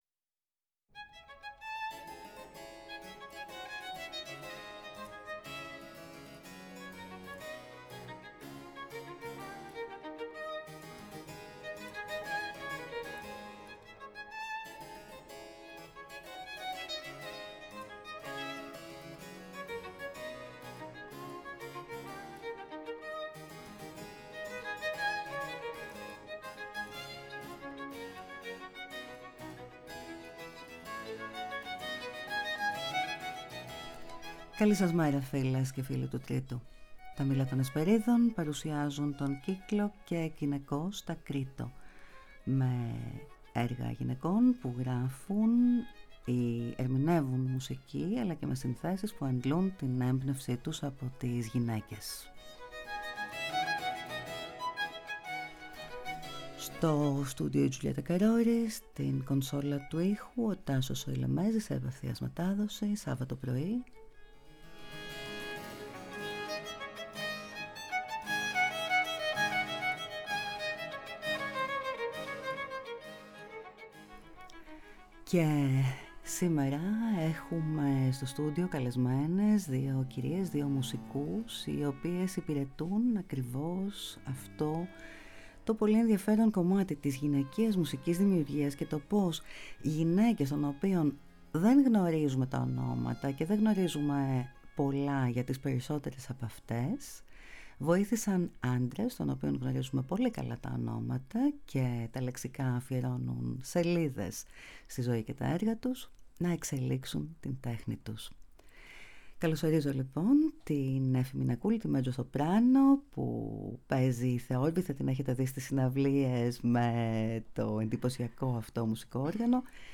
Τελικά μπορεί να ταιριάξει ο ήχος του σπινέτου η του τσέμπαλου στις αριέτες των Bellini, Donizetti, Rossini? Την συζήτηση διανθίζουν συνθέσεις των Luzzaschi, Monteverdi, Rossi,Handel, Paisiello, Donizetti, Rossini, απολαμβάνοντας την γυναικεία φωνή σε διάφορους συνδυασμούς που αναδεικνύουν τις εκλεκτικές συγγένειες του μπαρόκ με τον πρώιμο ρομαντισμό.
και εκ Γυναικος τα Κρειττω Μουσική Συνεντεύξεις